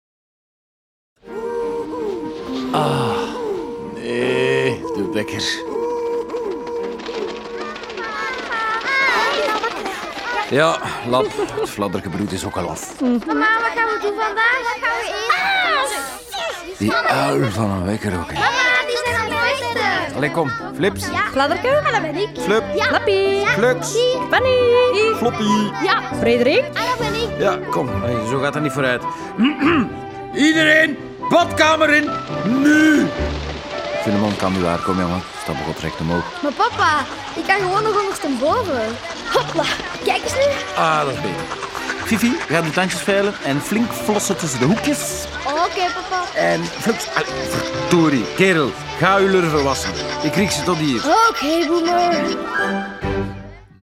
De rollen worden ingesproken door de béste acteurs en in bijhorend prentenboek staat ook een voorleestekst.
Heerlijk hoorspel